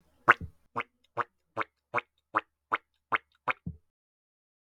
Spongebob Walking - Bouton d'effet sonore